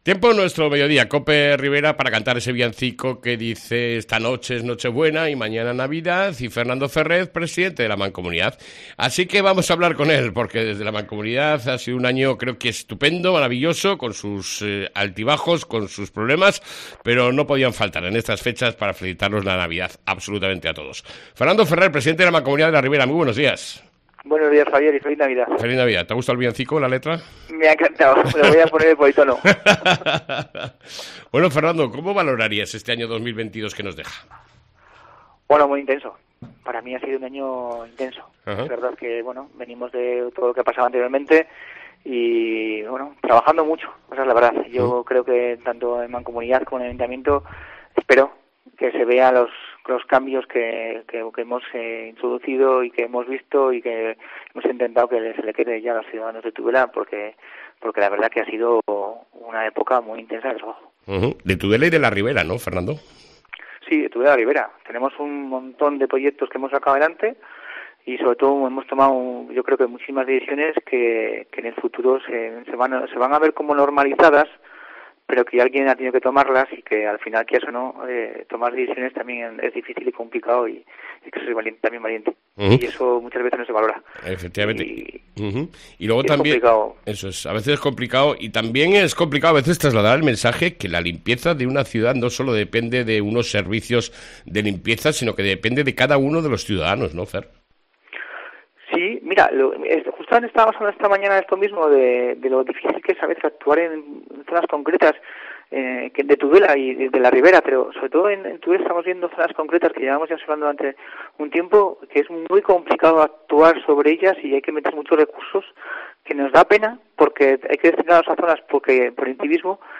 ENTREVISTA CON FERNANDO FERRER, PRESIDENTE DE LA MANCOMUNIDAD DE LA RIBERA